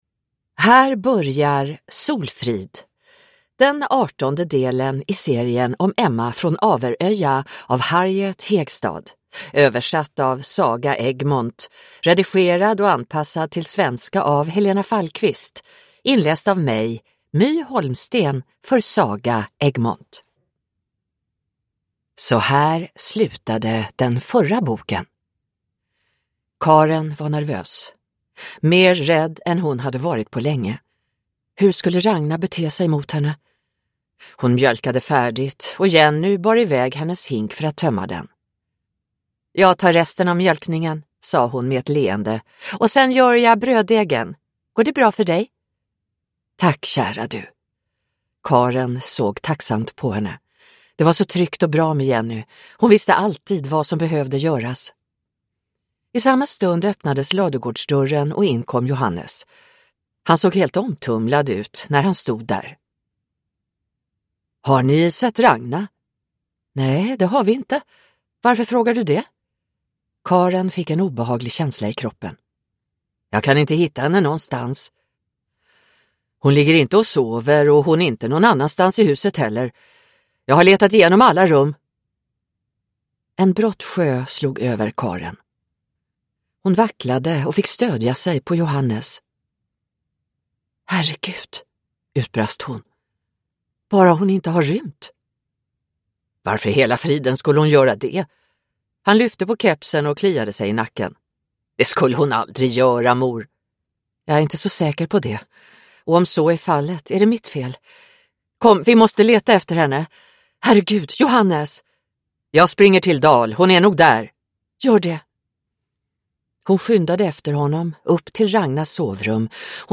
Solfrid – Ljudbok